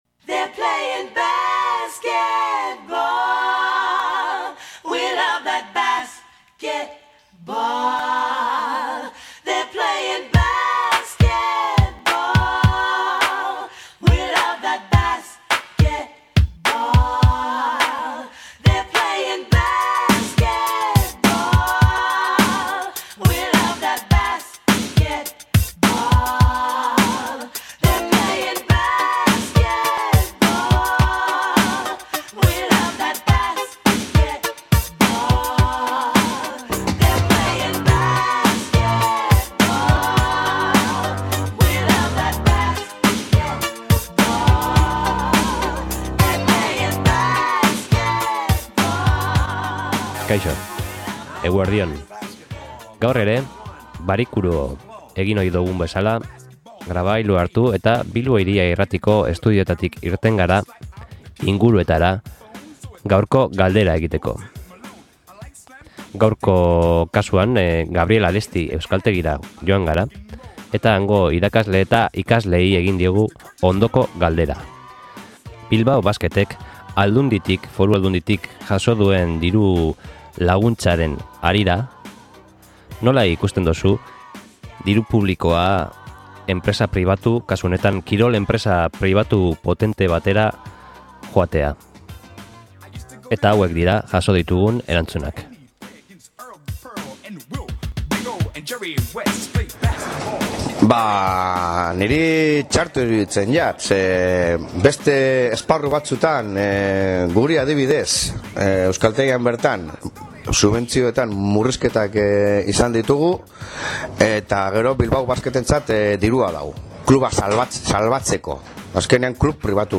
INKESTA
SOLASALDIA